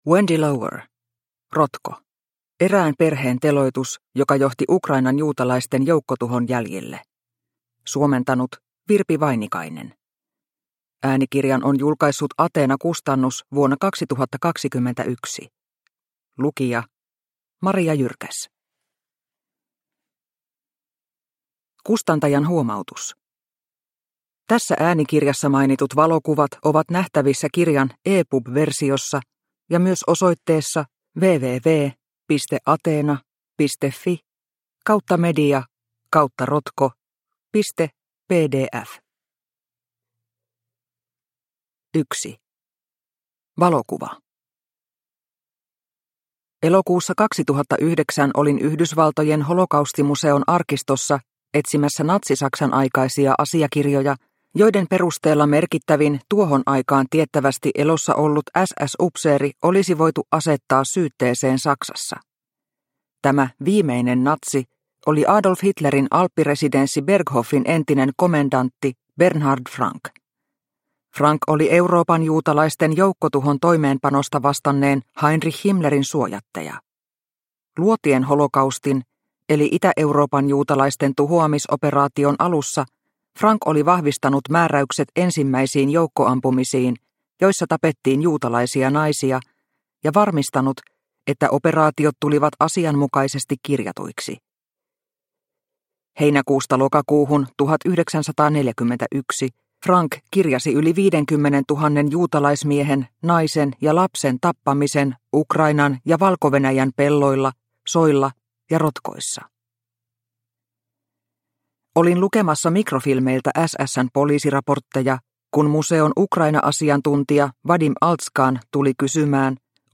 Rotko – Ljudbok – Laddas ner